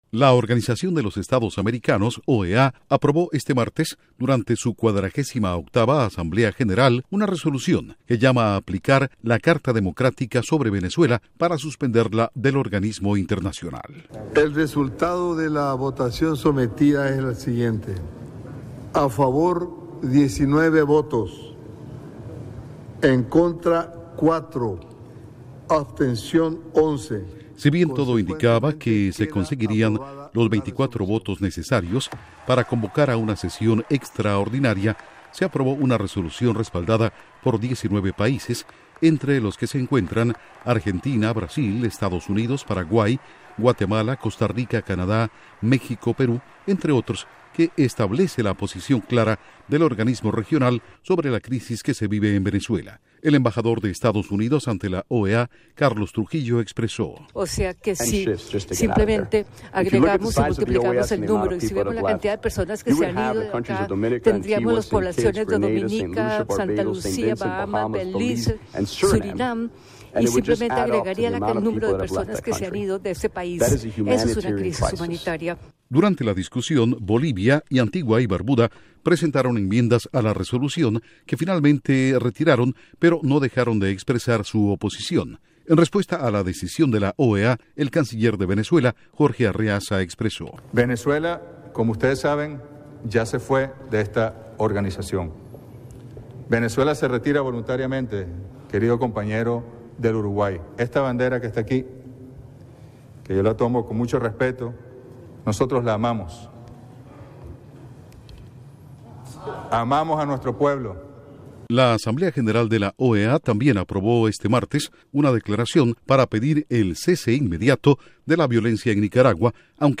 Asamblea General de la OEA aprueba resolución sobre Venezuela para suspenderla del organismo multilateral, y una declaración sobre Nicaragua pidiendo el cese inmediato de la violencia. Informa desde la Voz de América en Washington